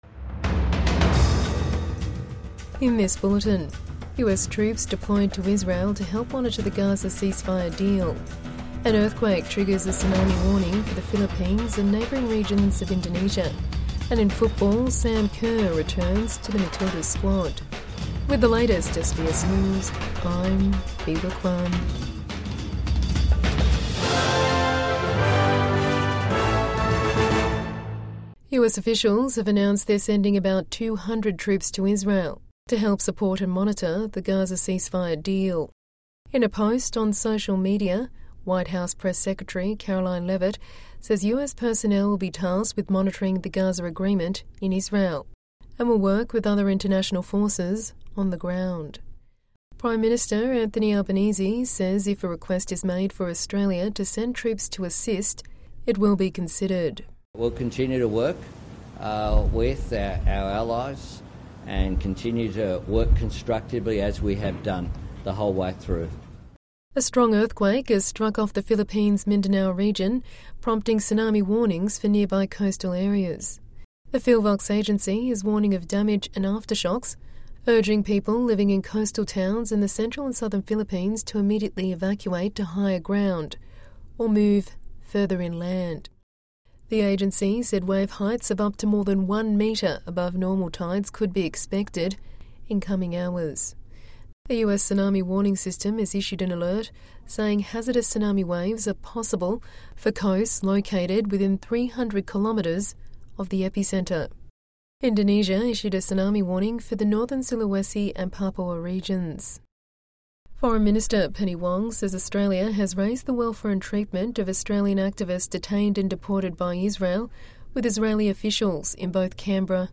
US troops deployed to help monitor Gaza ceasefire deal | Evening News Bulletin 10 October 2025